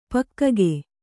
♪ pakkage